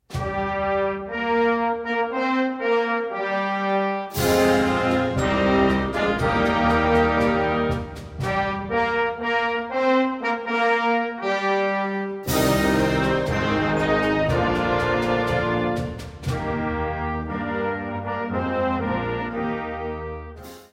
Catégorie Harmonie/Fanfare/Brass-band
Sous-catégorie Folklore international